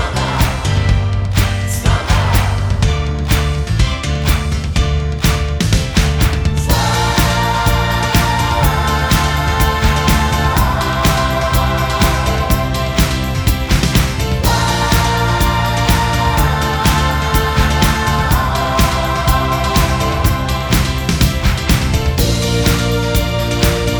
no Backing Vocals Dance 3:31 Buy £1.50